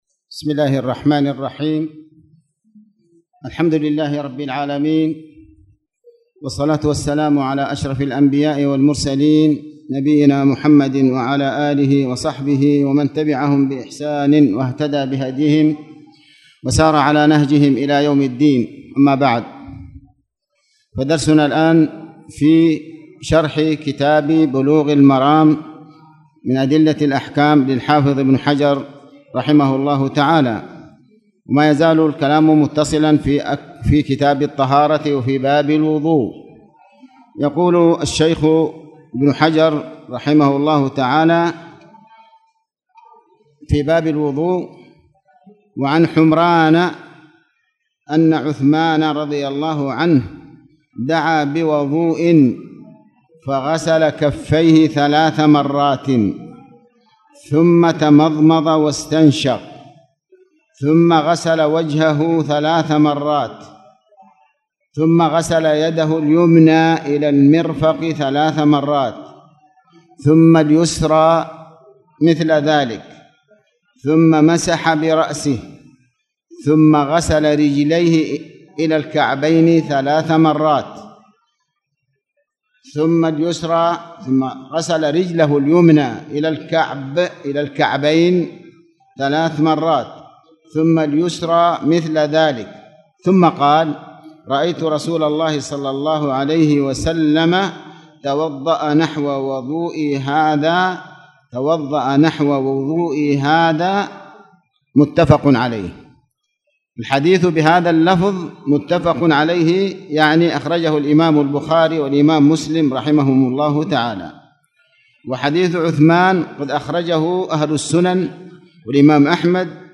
تاريخ النشر ٣٠ ربيع الأول ١٤٣٨ هـ المكان: المسجد الحرام الشيخ